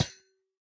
Minecraft Version Minecraft Version snapshot Latest Release | Latest Snapshot snapshot / assets / minecraft / sounds / block / copper_grate / step5.ogg Compare With Compare With Latest Release | Latest Snapshot